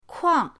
kuang4.mp3